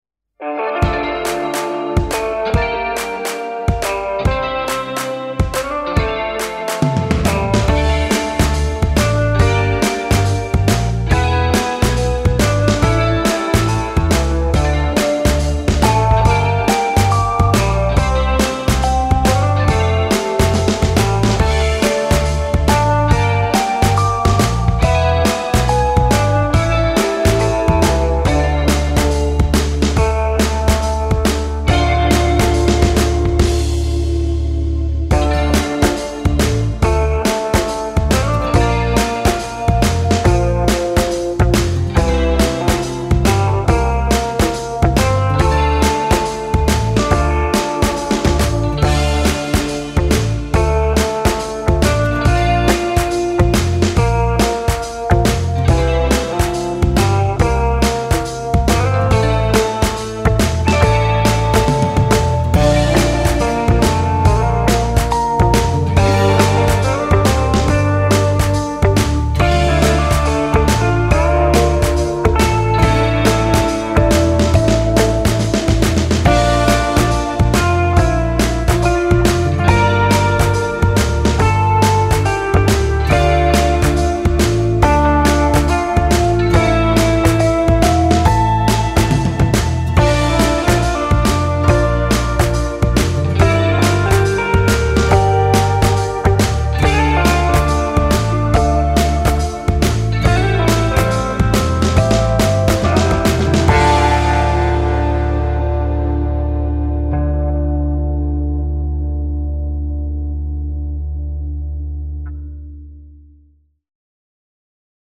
guitare - harpe - aerien - folk - melodieux